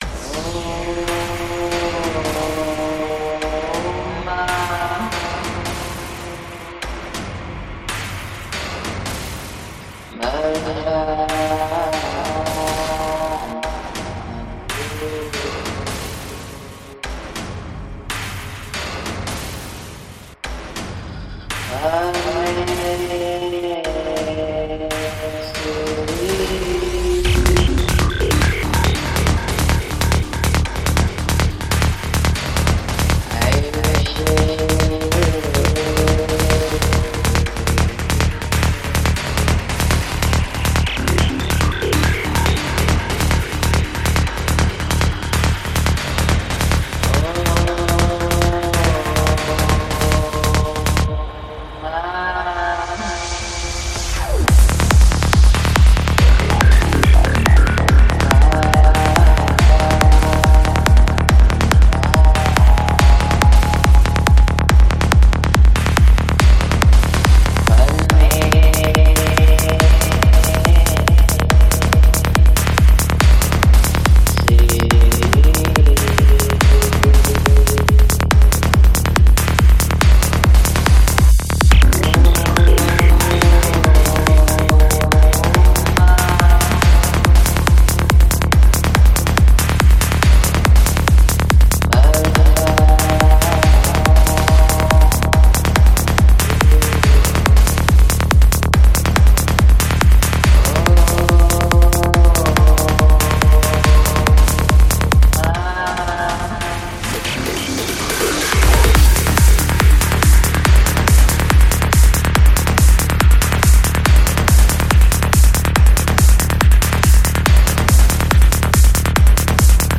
Жанр: Trance
Psy-Trance